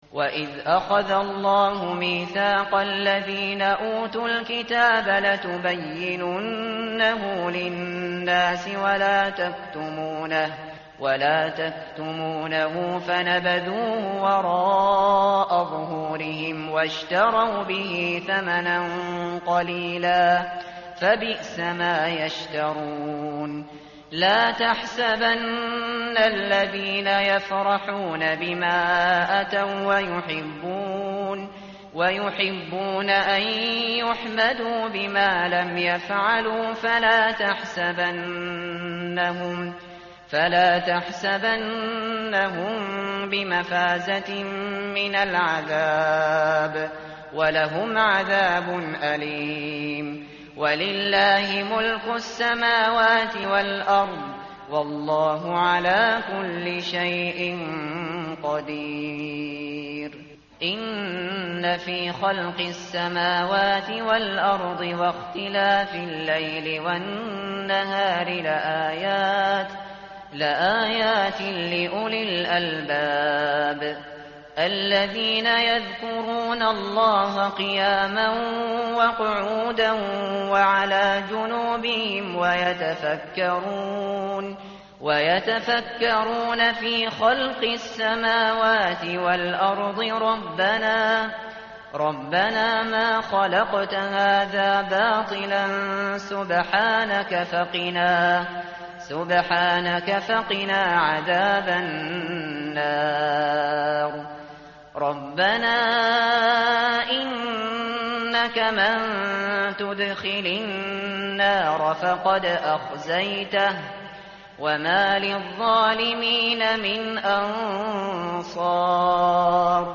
متن قرآن همراه باتلاوت قرآن و ترجمه
tartil_shateri_page_075.mp3